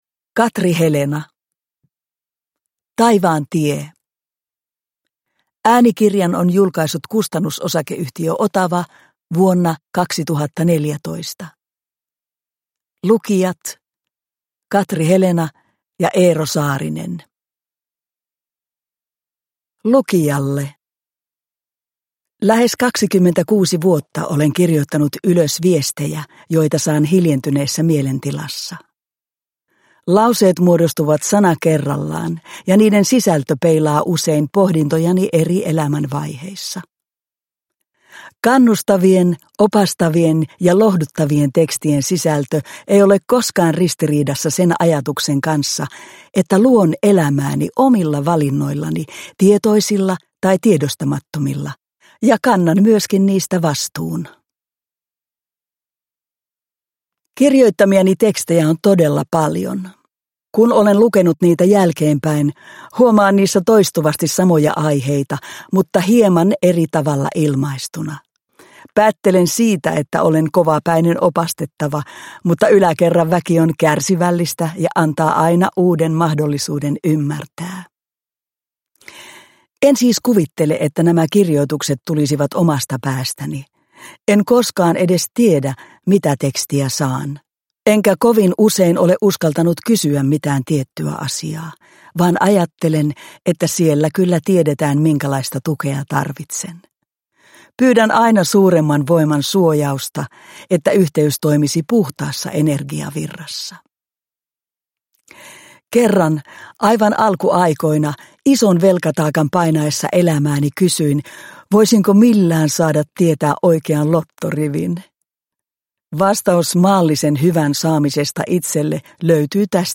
Taivaan tie – Ljudbok – Laddas ner